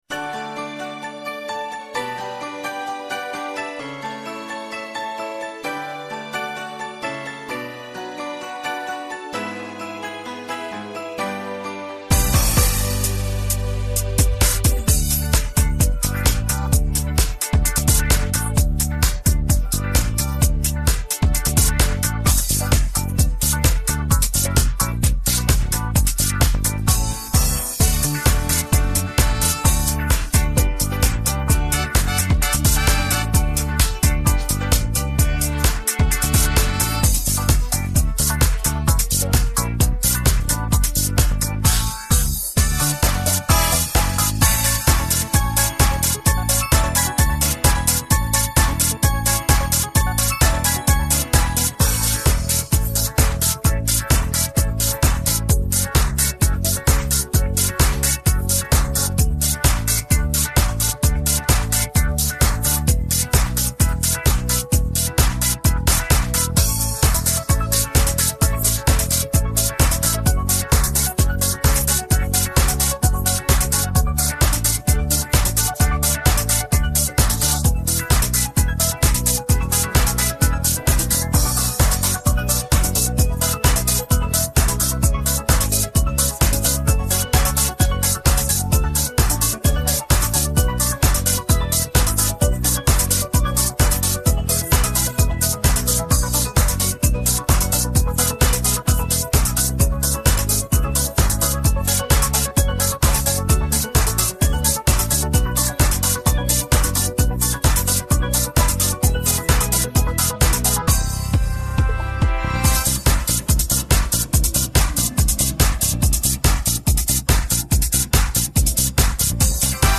• Категория: Детские песни
караоке
минусовка